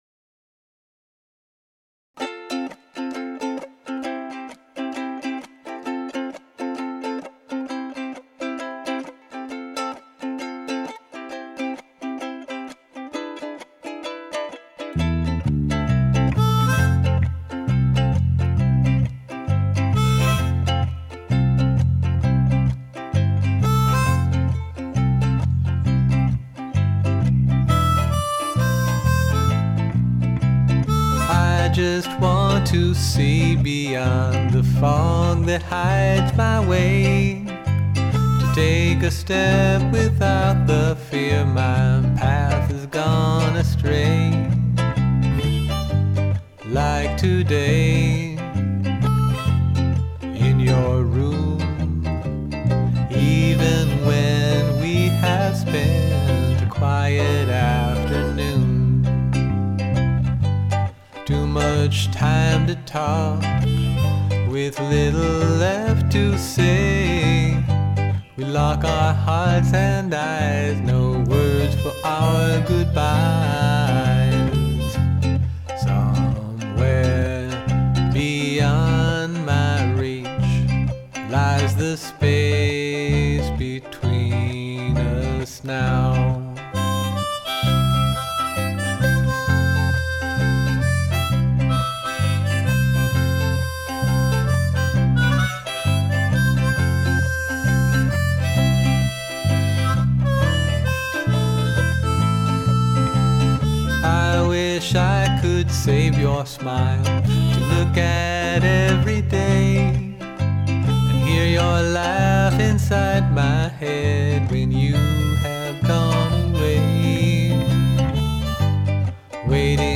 vocals, guitar, mandolin, ukulele, bass, percussion, drums
harmonica